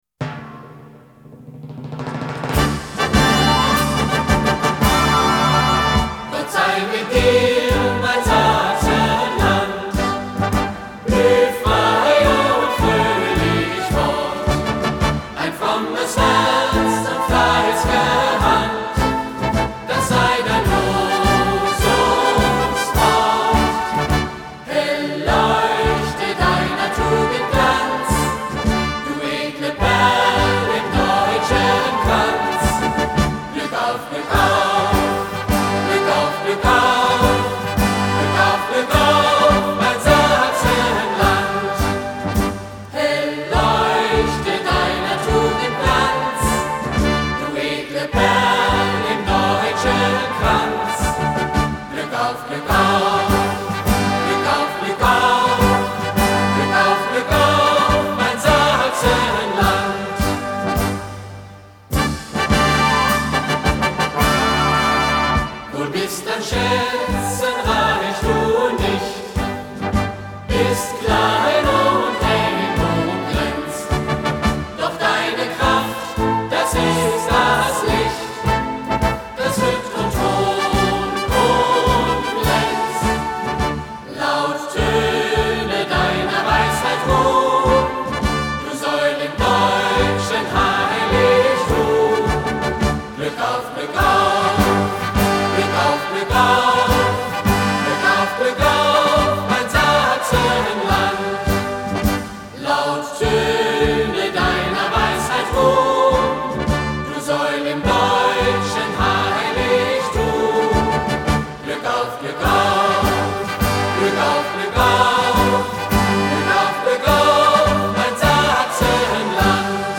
Hymnen: